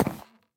Minecraft Version Minecraft Version 1.21.5 Latest Release | Latest Snapshot 1.21.5 / assets / minecraft / sounds / block / hanging_sign / step2.ogg Compare With Compare With Latest Release | Latest Snapshot